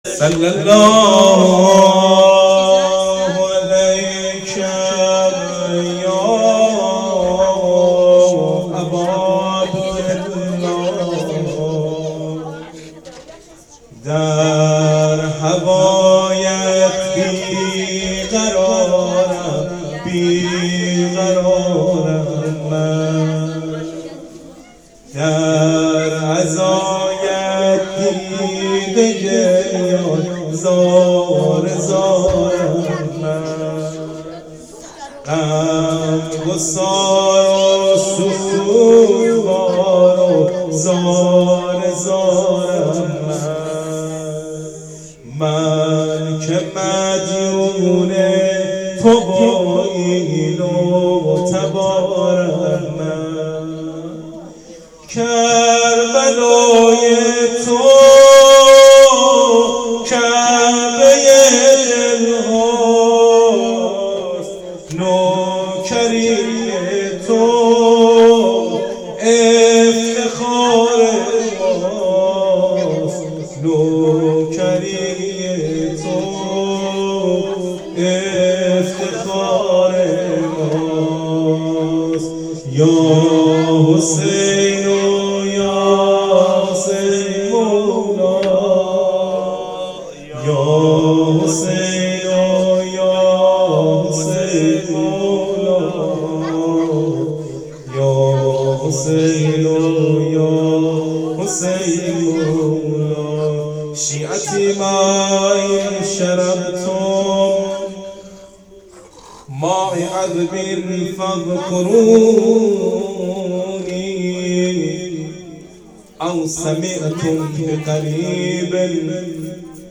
روضه روز سیزدهم محرم